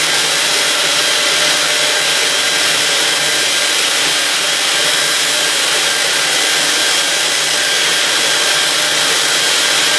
Звук перфоратора